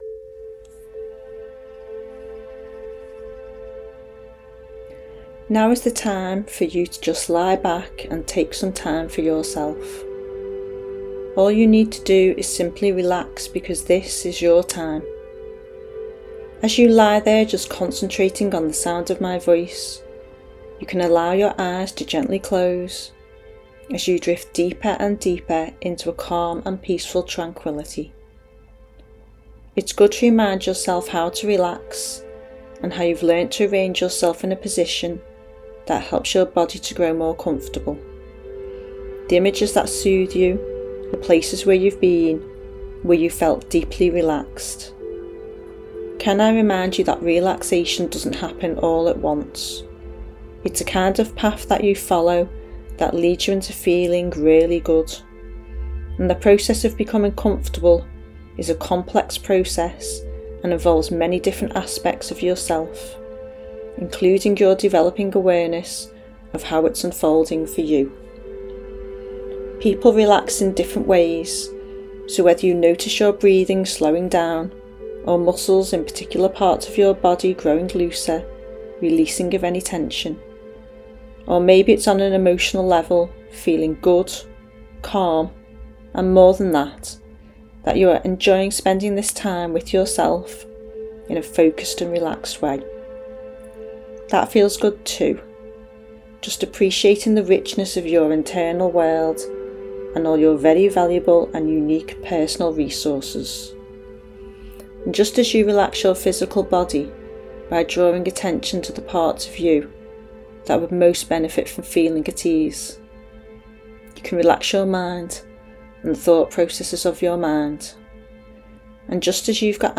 This guided relaxation track is free to use, you can listen to it directly from this webpage. The best time to listen is before you go to sleep, it will help you relax and help you improve your sleep.